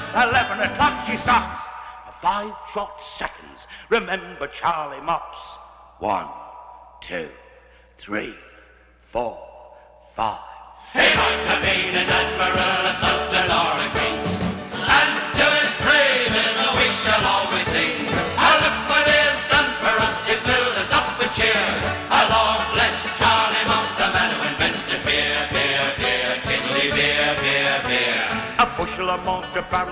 Акустические струны и тёплый вокал
Жанр: Фолк